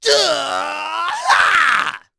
Ezekiel-Vox_Casting2.wav